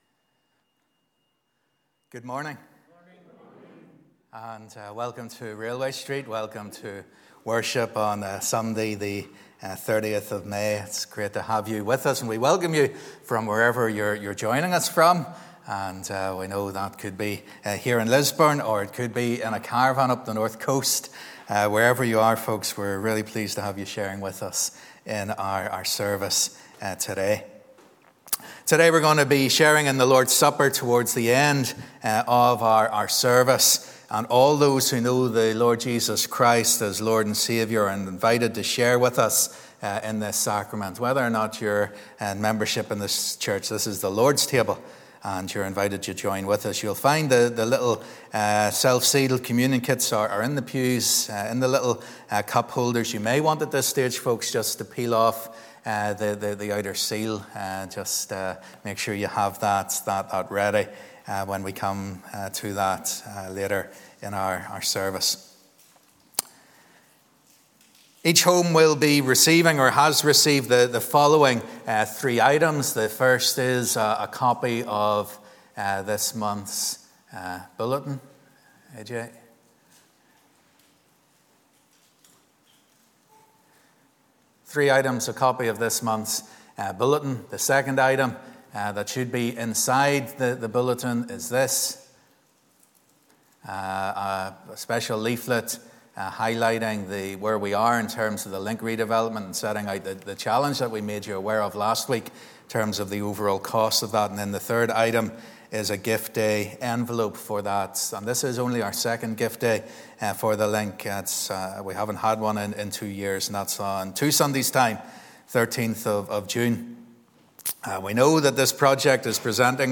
Live @ 10:30am Morning Service